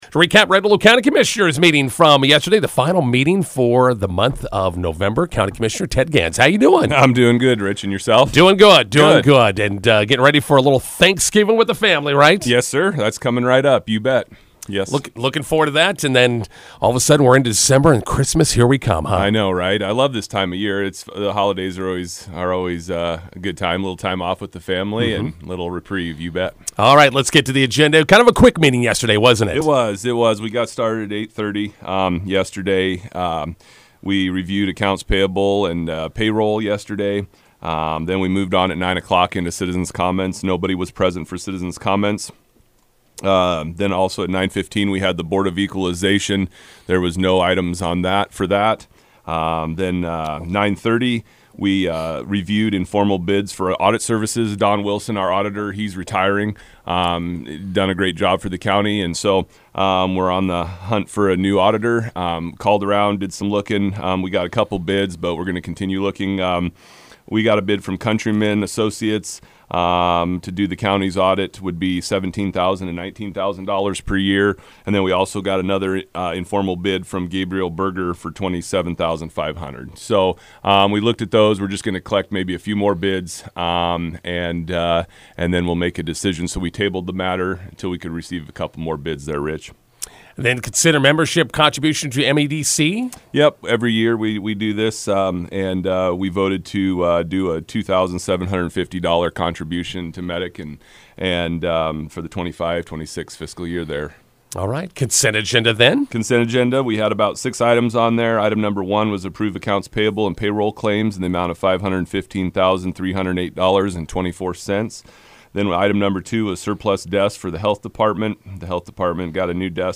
INTERVIEW: Red Willow County Commissioners meeting recap with County Commissioner Ted Gans.